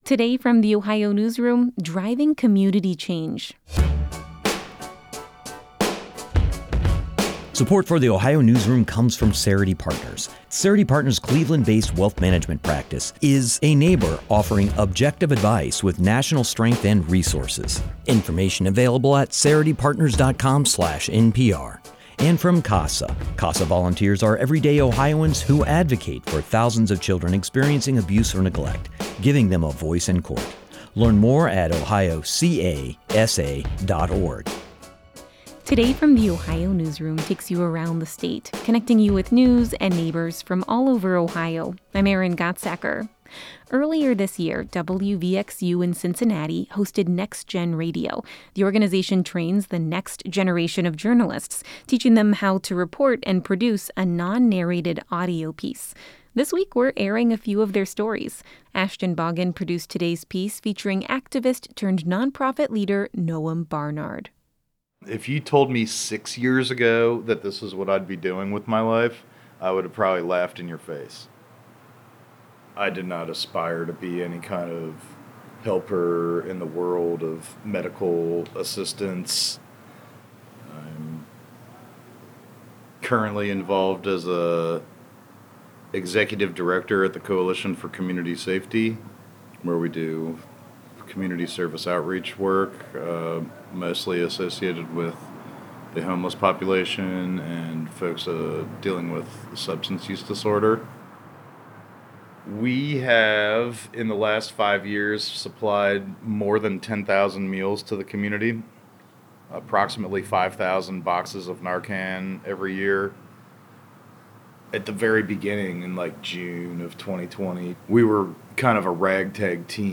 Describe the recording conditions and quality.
The organization trains the next generation of journalists, teaching them how to report and produce a non-narrated audio piece.